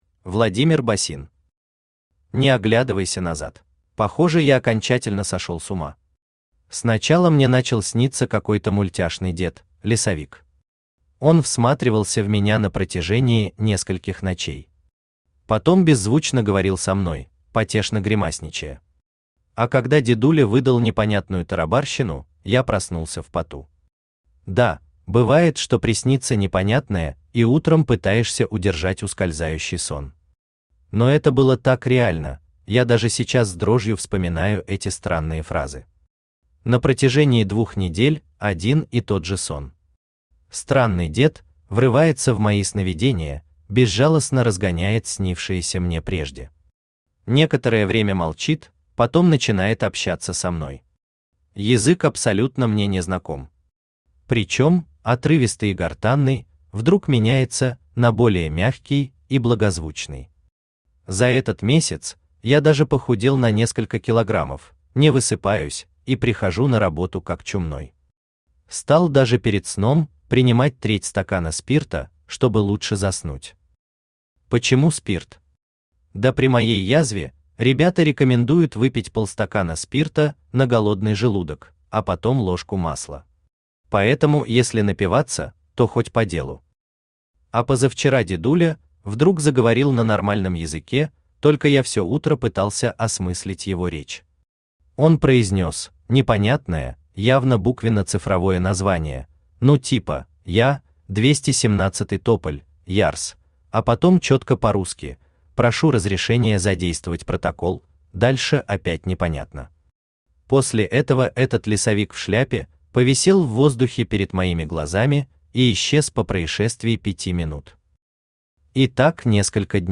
Aудиокнига Не оглядывайся назад Автор Владимир Георгиевич Босин Читает аудиокнигу Авточтец ЛитРес.